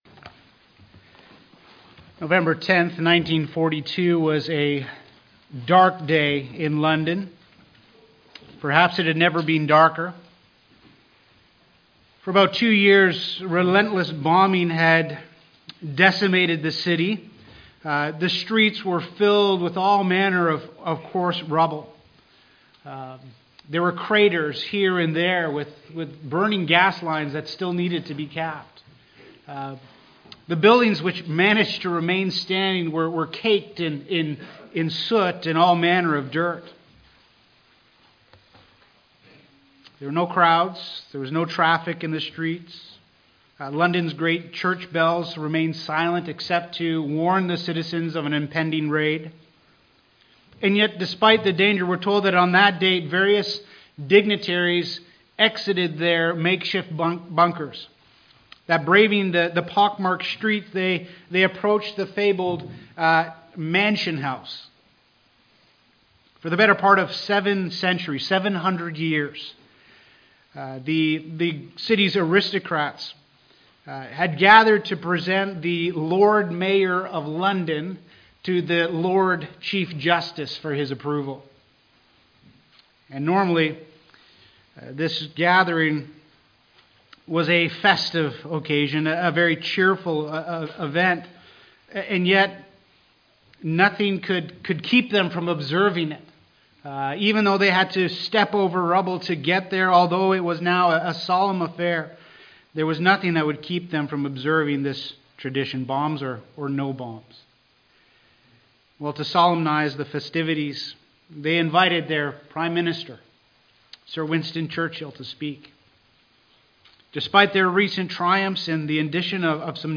Acts Sermon Series